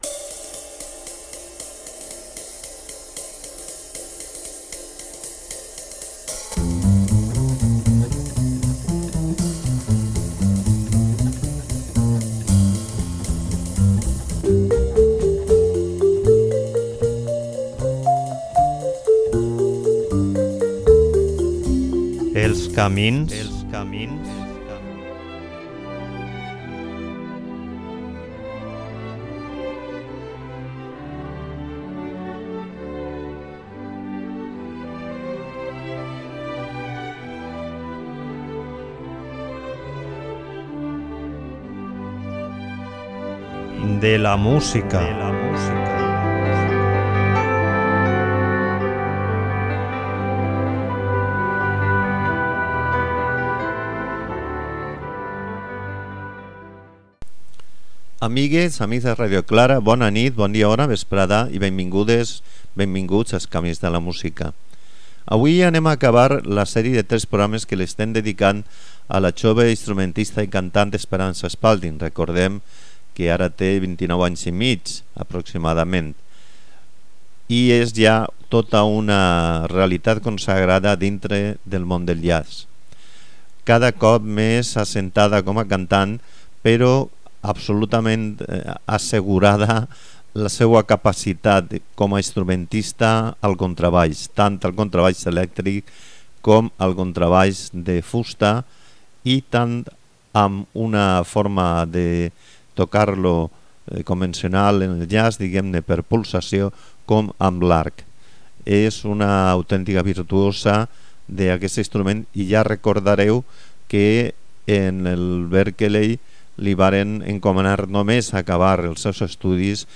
Fins ara no hi ha gravacions en disc, però hui podreu escoltar audios d'actuacions en directe i d'altres directes dels discos anteriors.